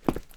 Footstep Concrete Walking 1_06.wav